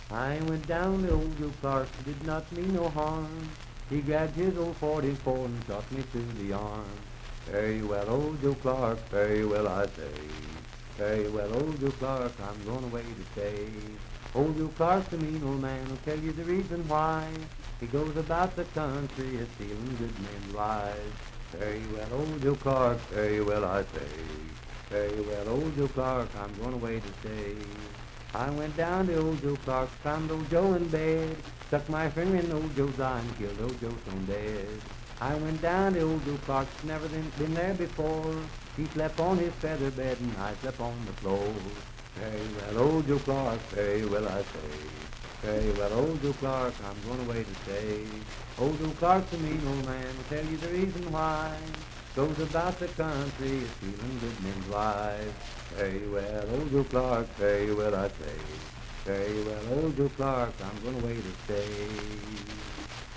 Unaccompanied vocal music
Dance, Game, and Party Songs
Voice (sung)
Parkersburg (W. Va.), Wood County (W. Va.)